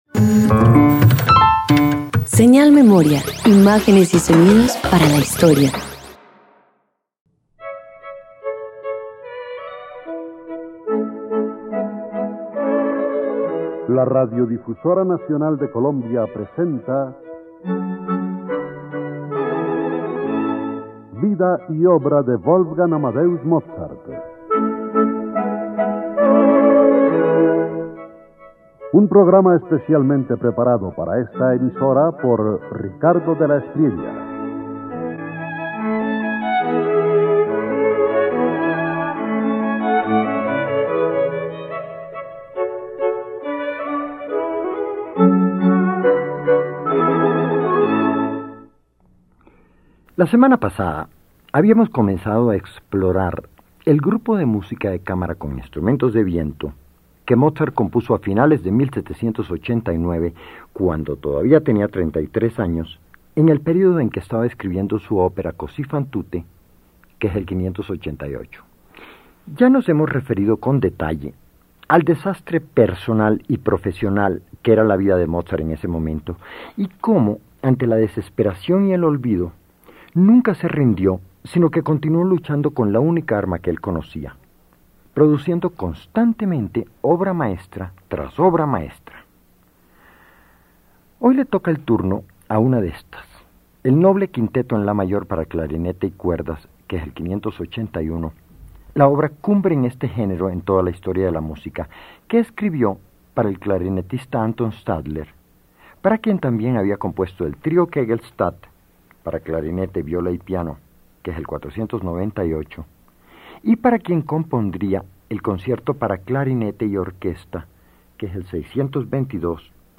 302 Quinteto para clarinete_1.mp3